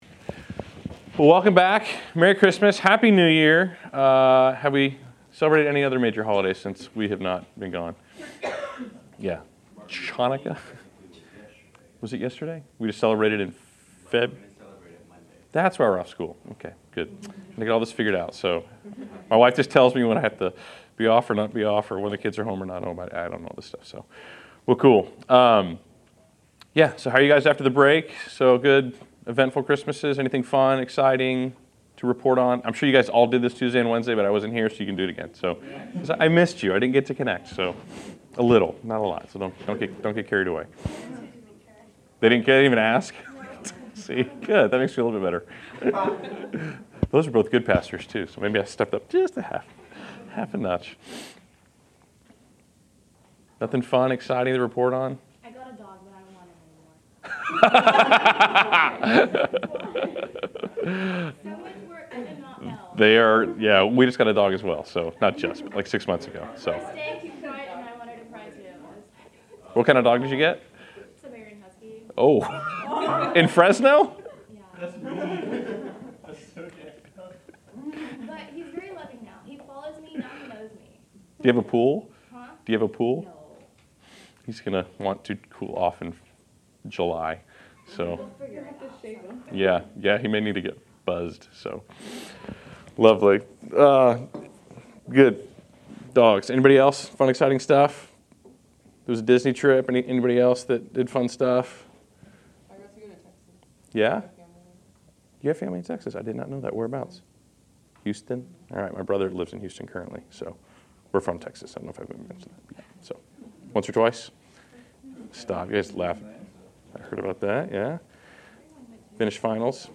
Class Session Audio